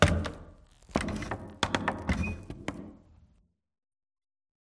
amb_fs_stumble_wood_13.mp3